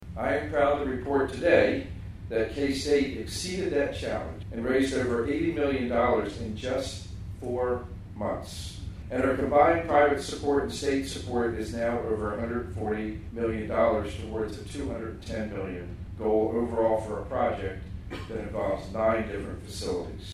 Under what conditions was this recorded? A groundbreaking ceremony for a new Agronomy Research and Innovation Center at Kansas State University had to be moved indoors Monday, due to rain, leading to a standing-room only crowd of guests inside the university’s Agronomy Education Center.